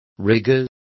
Complete with pronunciation of the translation of rigor.